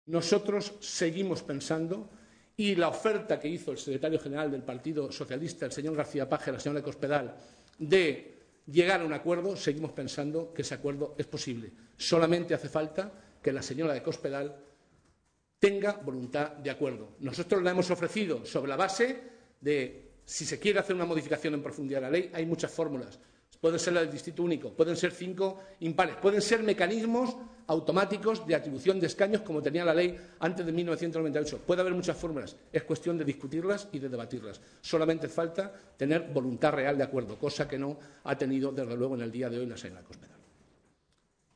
José Molina, diputado regional del PSOE de Castilla-La Mancha
Cortes de audio de la rueda de prensa